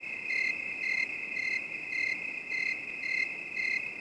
Index of /90_sSampleCDs/E-MU Producer Series Vol. 3 – Hollywood Sound Effects/Ambient Sounds/Outdoor Ambience
CALM NIGHT-L.wav